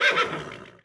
pony_damage.wav